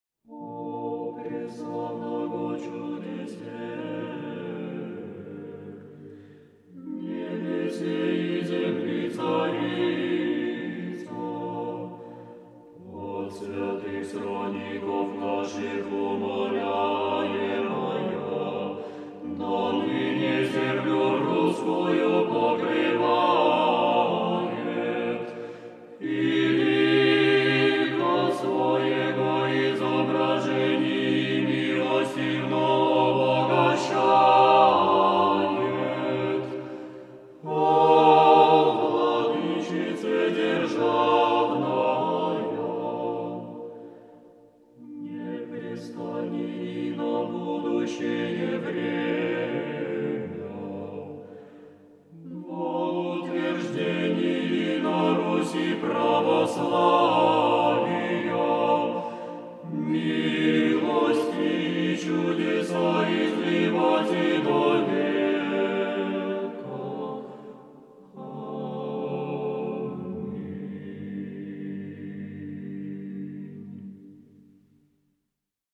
Предлагаем для прослушивания песнопения хорового коллектива.